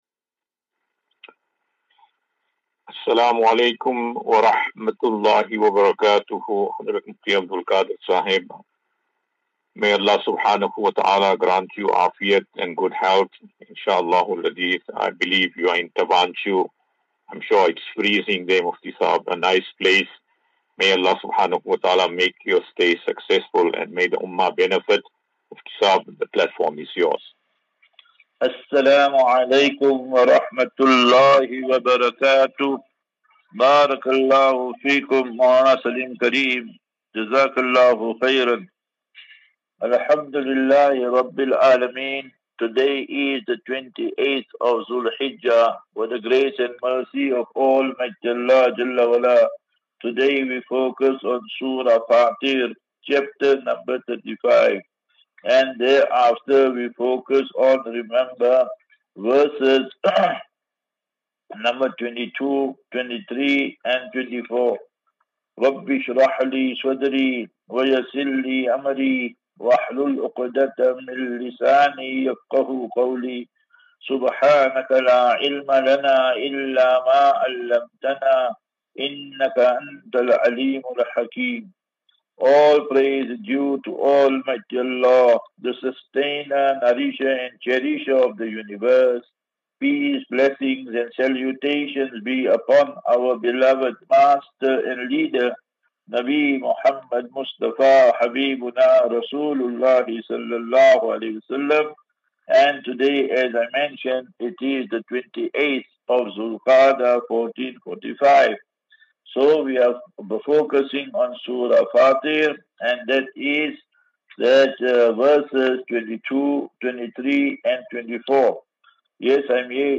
6 Jun 06 June 2024. Assafinatu - Illal - Jannah. QnA